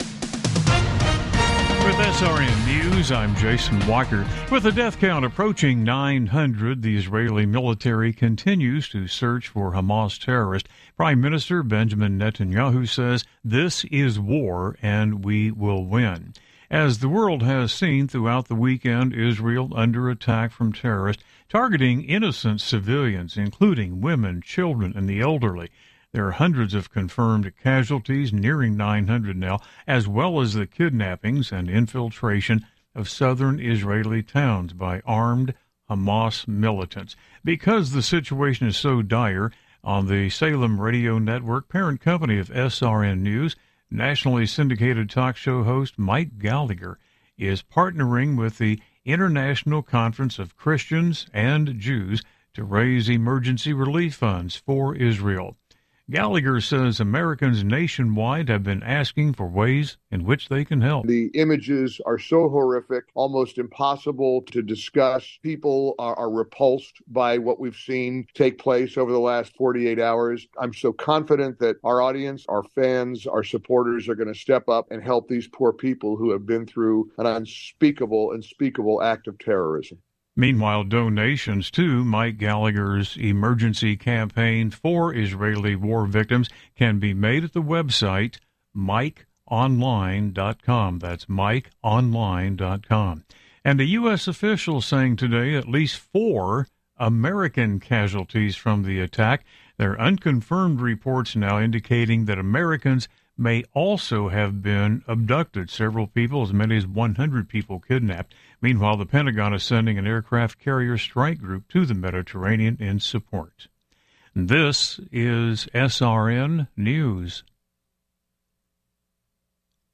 Top News Stories Oct 08, 2023 – 06:00 PM CDT